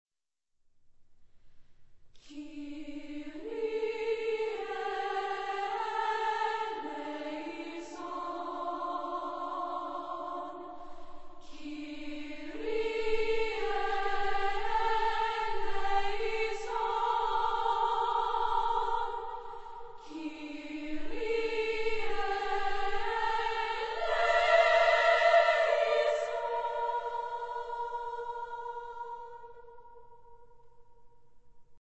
Epoque: 20th century
Genre-Style-Form: Sacred ; Mass
Type of Choir: SSA  (3 women voices )